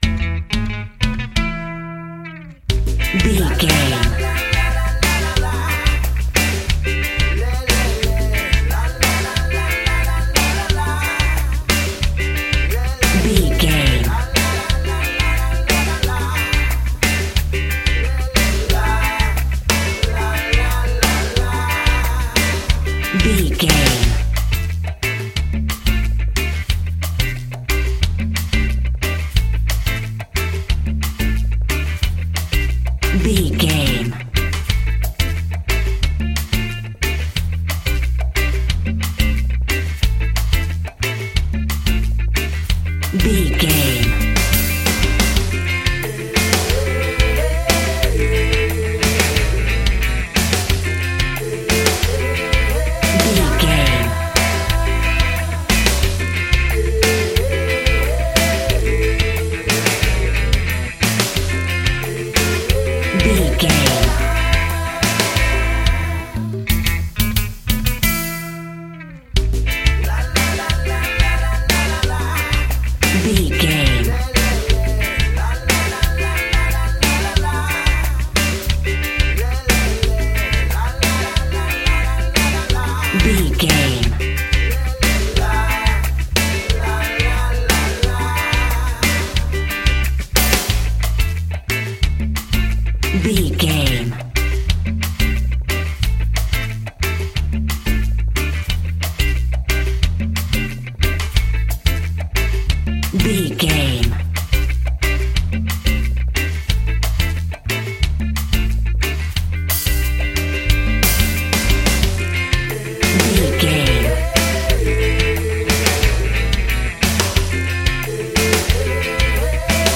Uplifting
Aeolian/Minor
maracas
percussion spanish guitar
latin guitar